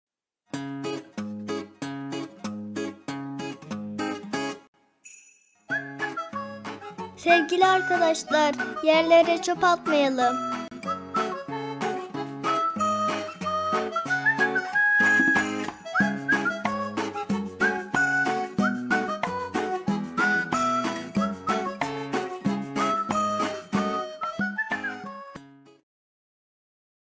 Yerlere Çöp Atmayalım Anonsu (mp3)